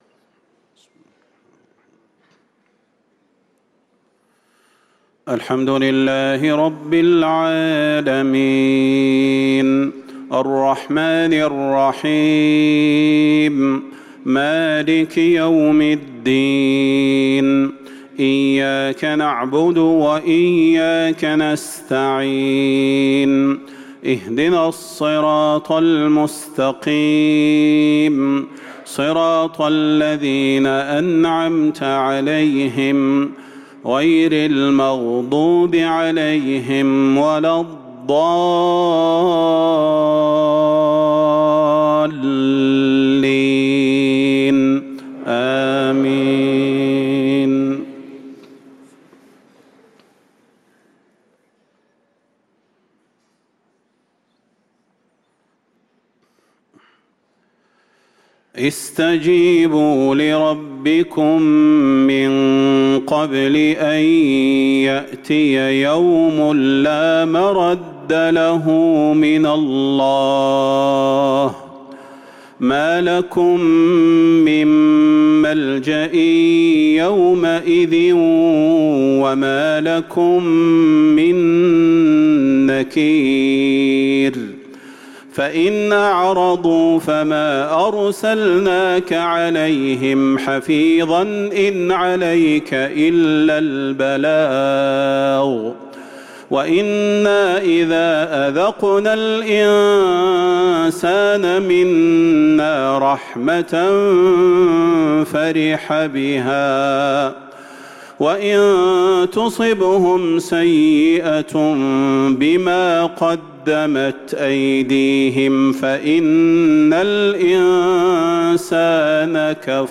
صلاة العشاء للقارئ صلاح البدير 14 جمادي الآخر 1445 هـ